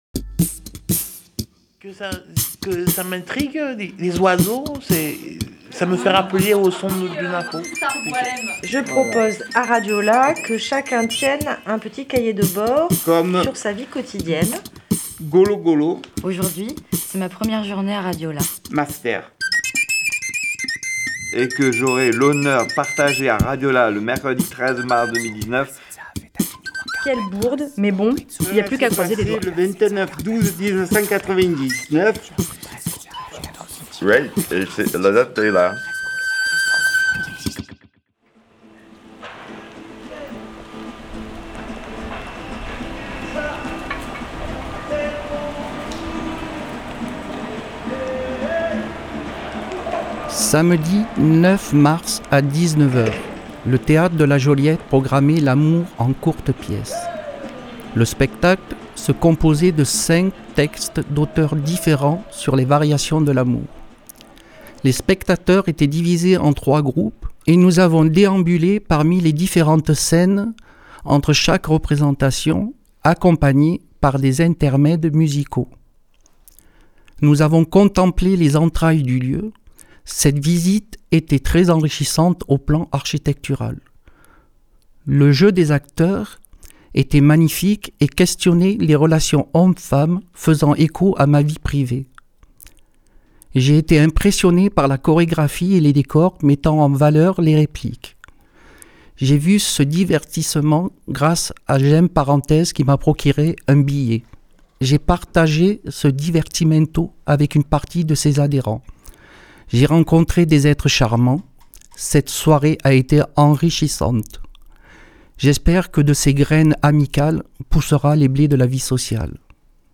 Une série de programmes réalisés par les Grenouilles et les participants de l'atelier Radio-Là qui réunit des personnes concernées par la psychiatrie, à l'occasion des Semaines d'Information de la Santé Mentale.
Depuis janvier, chaque mercredi, nous avons consacré un petit bout de l’atelier à écrire chacun.e un témoignage, une expérience, un moment de notre vie quotidienne sur un bout de papier. Puis nous avons, ensemble, tout relu, tout trié, pour n’en garder que quelques-uns, que nous avons enregistrés. D’autres paroles ont été récoltées dans des moments de l’atelier, vous les entendrez aussi.